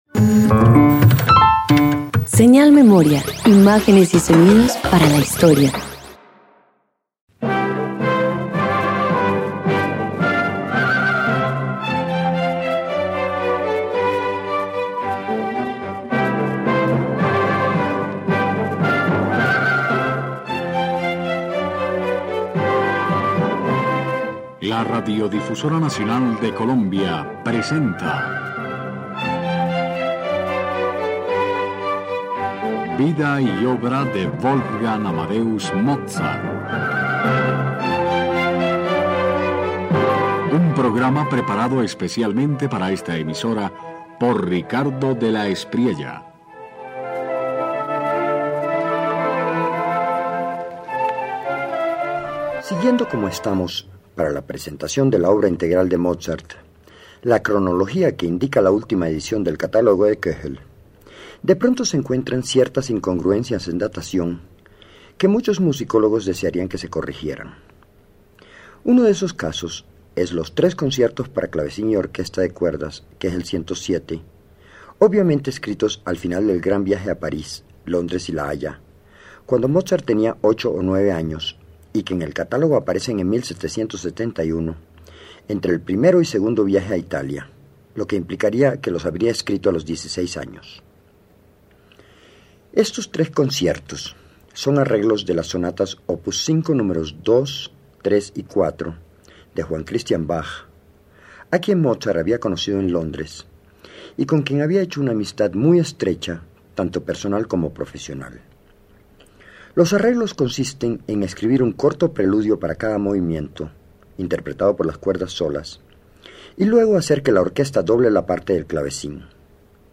En ellos el clavecín juega a ser orquesta y la orquesta, eco. Nada pesa: hay transparencia de taller, voluntad de entender el mecanismo.
051 Tres conciertos para clavecín y orquesta de cuerdas_1.mp3